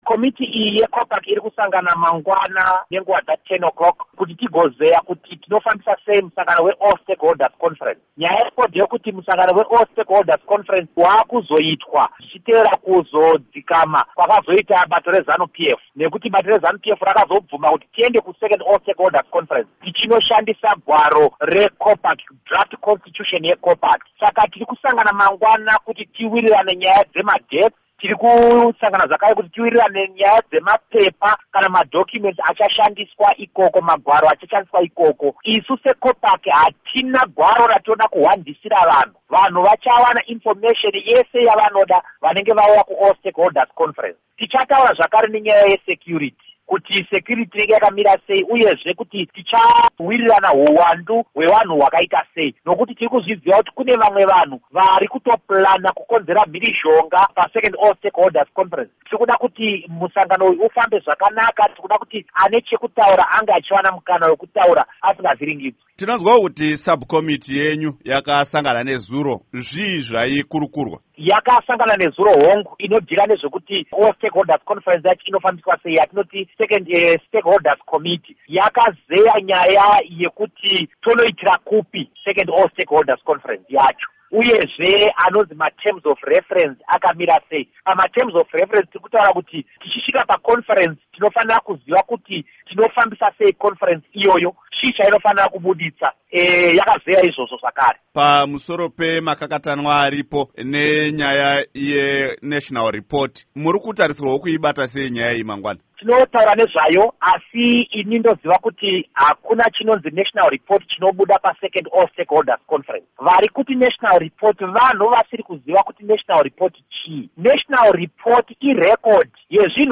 Embed share Hurukuro naVaDouglas Mwonzora by VOA Embed share The code has been copied to your clipboard.